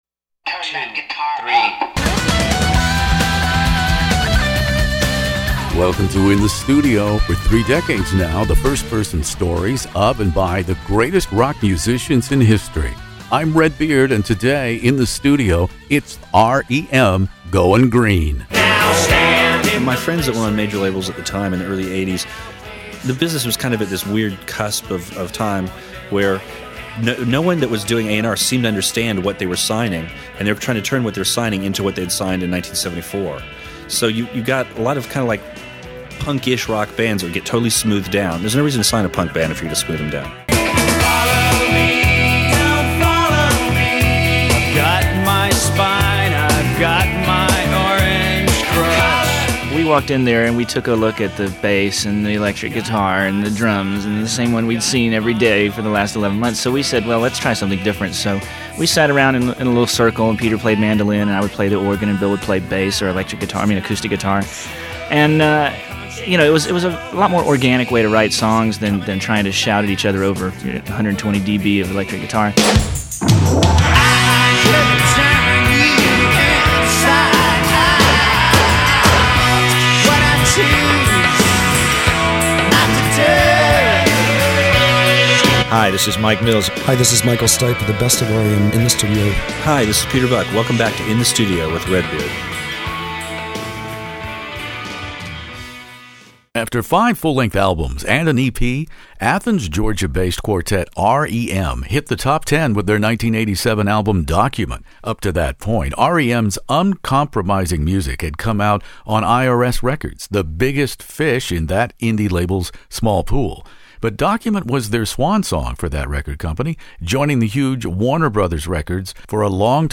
One of the world's largest classic rock interview archives, from ACDC to ZZ Top, by award-winning radio personality Redbeard.
Stipe, Buck, and Mills guest here In the Studio for R.E.M.’s Green.